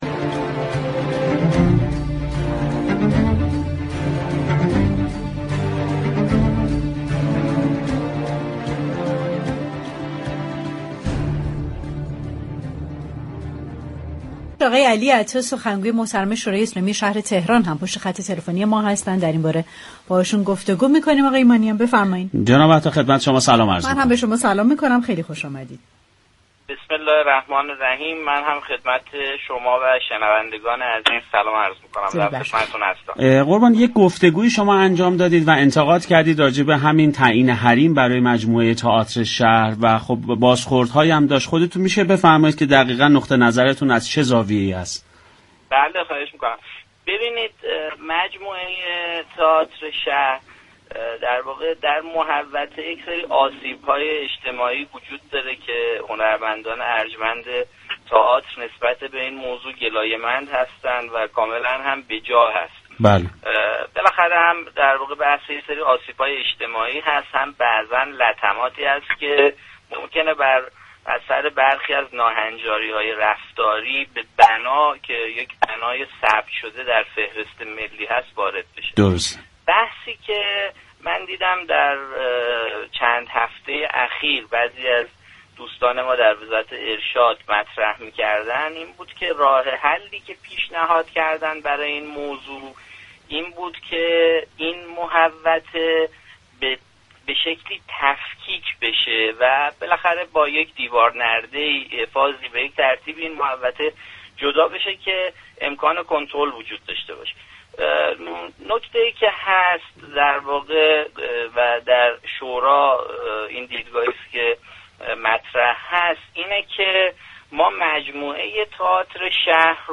به گزارش پایگاه اطلاع رسانی رادیو تهران، علی اعطا سخنگوی شورای اسلامی شهر تهران در گفتگو با برنامه پشت صحنه، درباره انتقادش از تعیین حریم برای تئاتر شهر گفت: مجموعه تئاتر شهر در محدوده ای قرار گرفته است كه آسیب‌های اجتماعی نیز دارد.